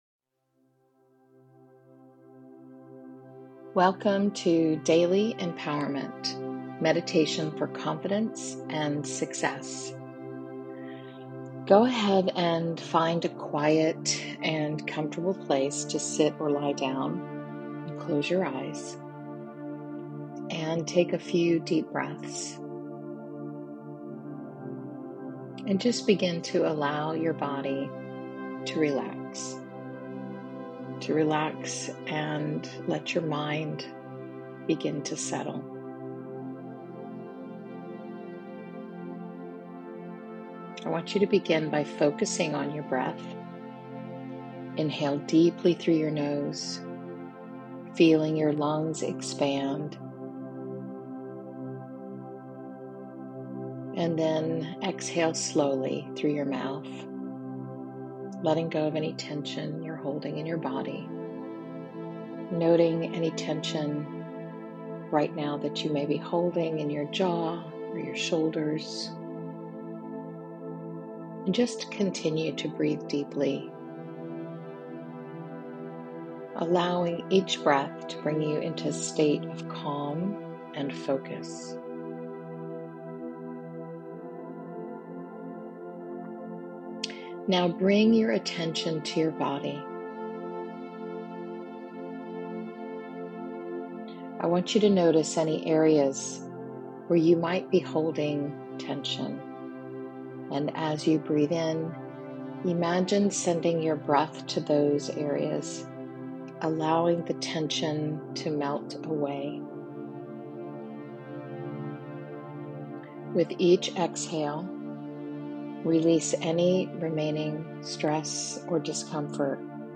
This meditation helps establish a sense of stability even when everything feels uncertain. We'll practice finding our inner anchor through breath and body awareness.